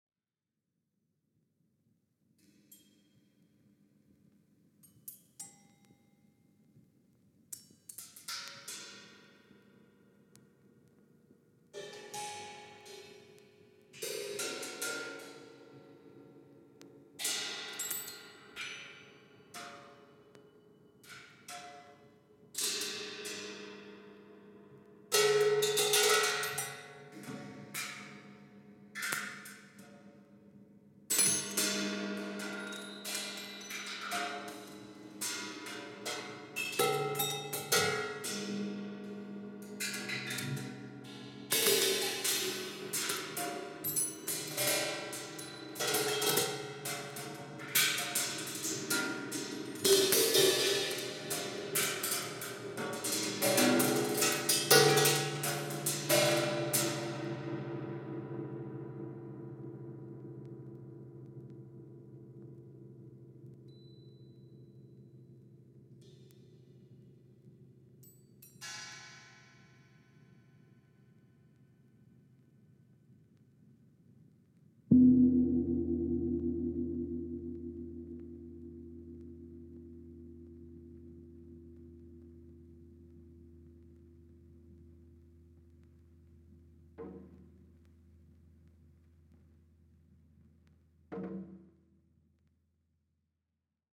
研ぎ澄まされた空気感に暖かさすら感じます！！！